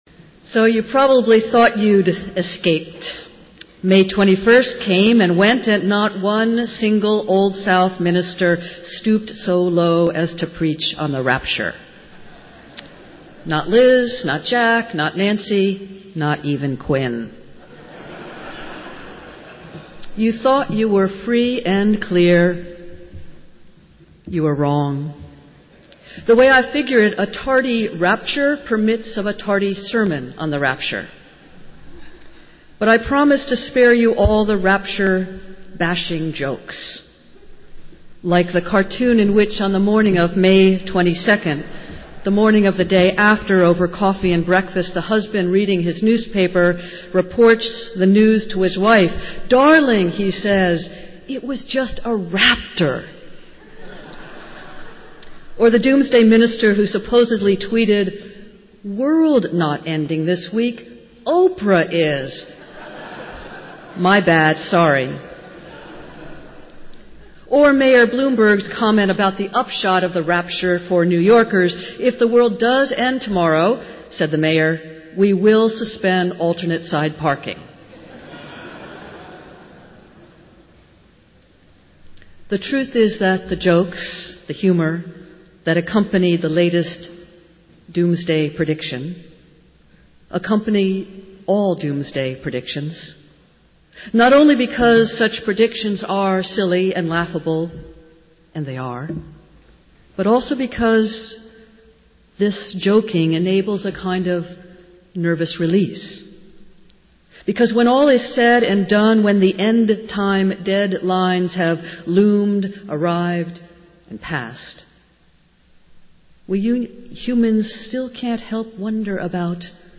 Festival Worship - Seventh Sunday of Easter